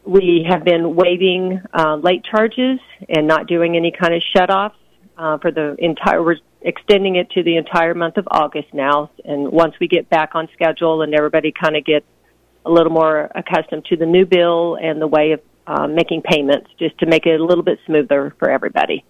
KVOE News.